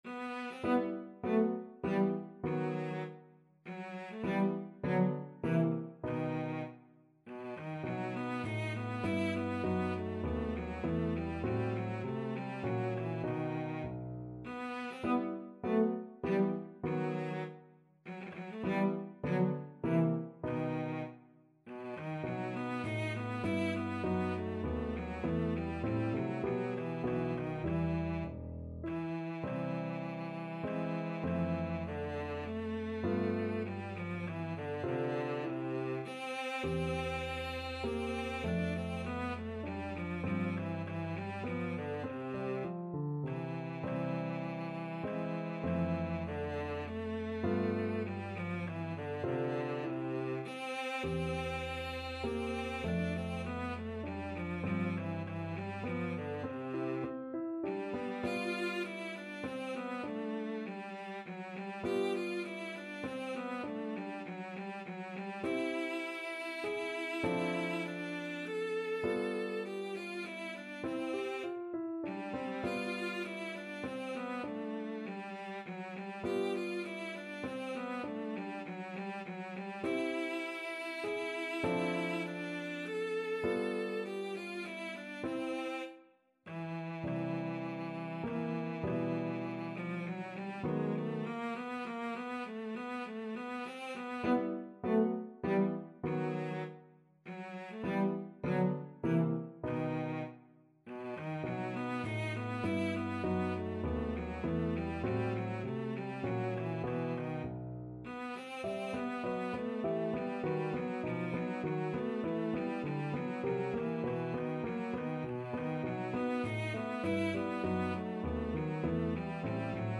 Cello
3/4 (View more 3/4 Music)
E minor (Sounding Pitch) (View more E minor Music for Cello )
Allegretto
Classical (View more Classical Cello Music)